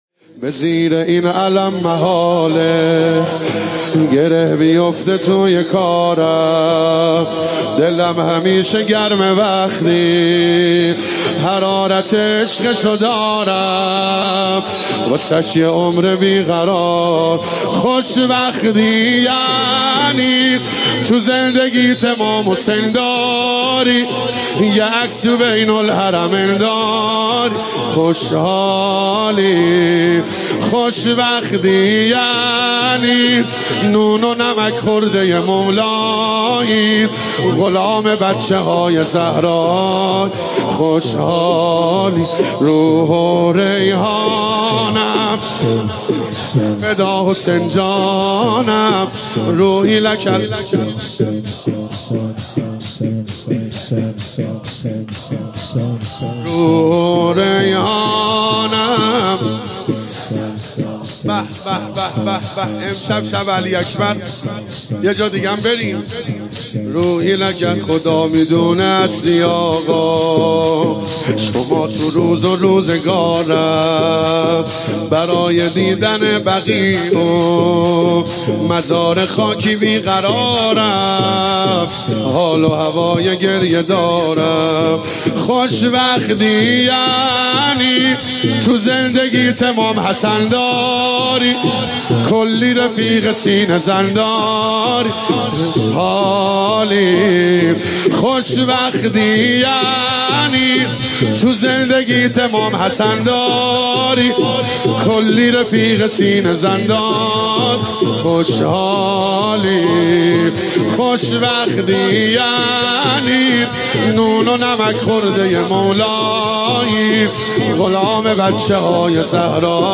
مداحی صوتی
شور